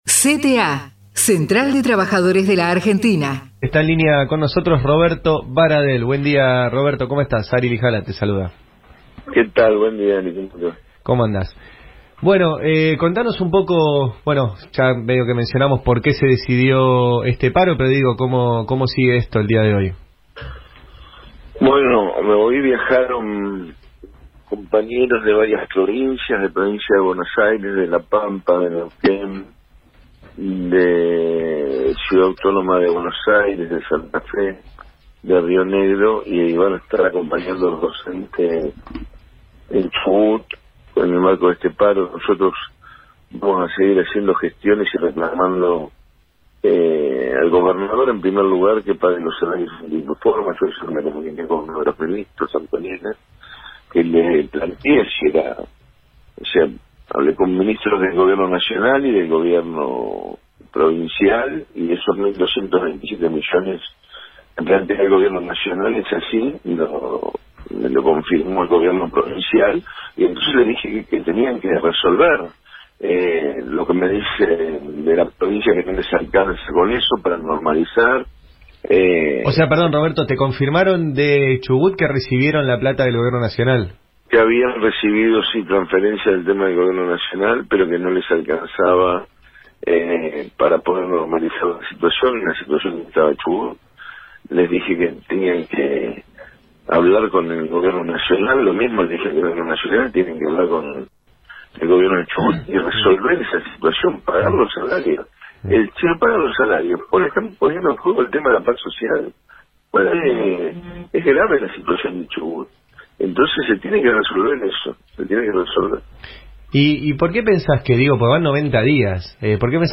ROBERTO BARADEL entrevistado en 'El Destape Radio'